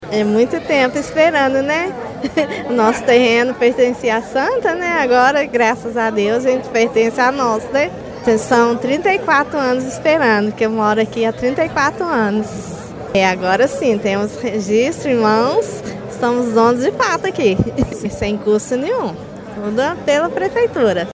Relatos colhidos durante o evento traduzem o impacto social da medida: